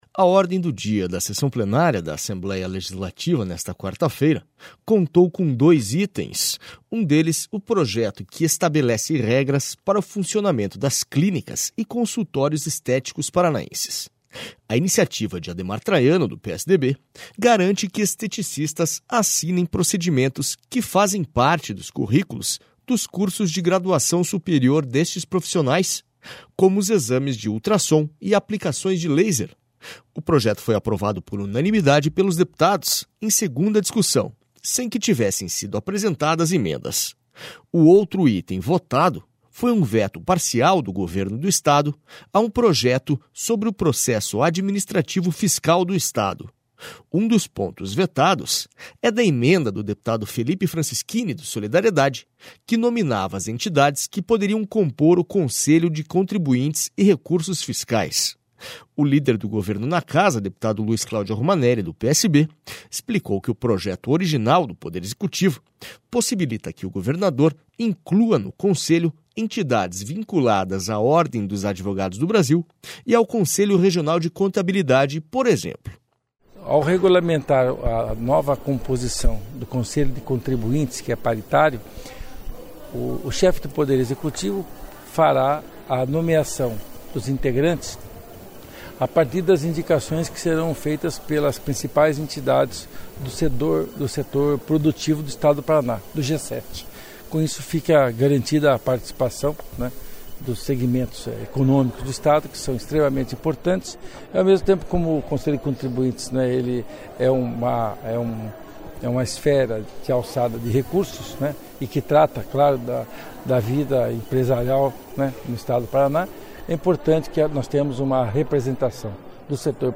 SONORA LUIZ CLAUDIO ROMANELLI